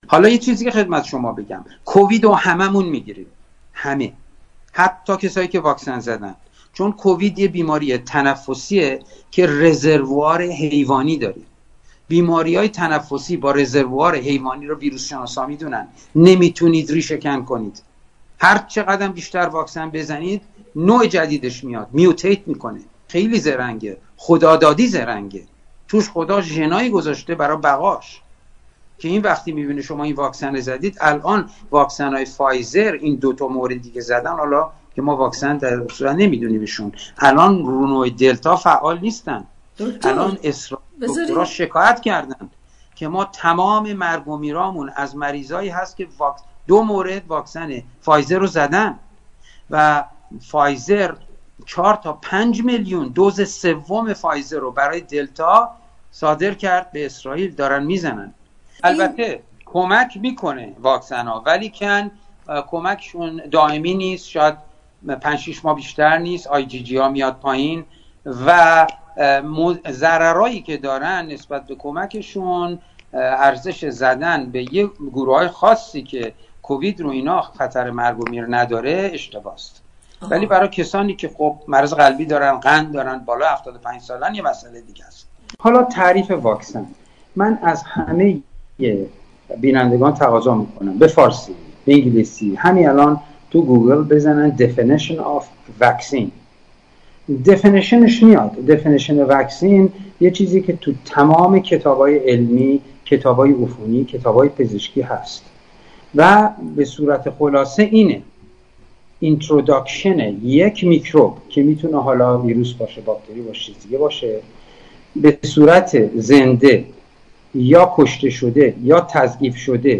گفتگوی مهم